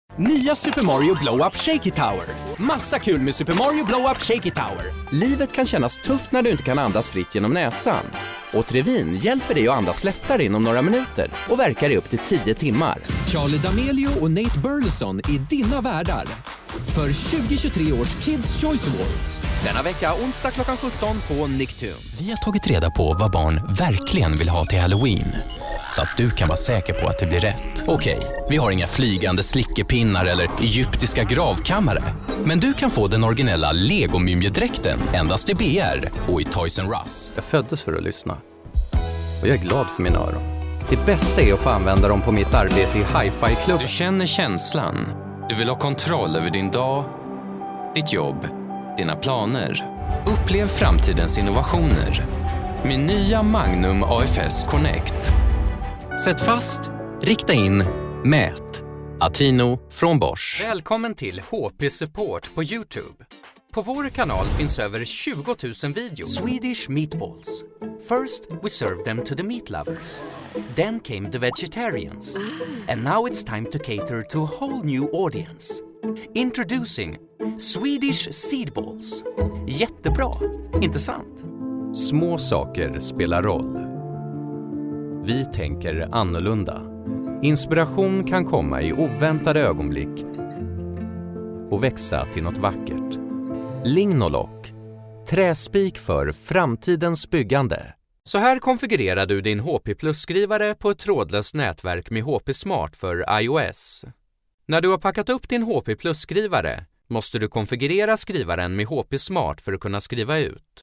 Male
Authoritative, Confident, Corporate, Engaging, Friendly, Warm, Young, Conversational, Energetic, Upbeat
English with Swedish/Scandinavian accent.
b_h_e-learning (ingen musik).mp3
Microphone: Neumann TLM-102
Audio equipment: Professional Sound Booth from Demvox, ECO100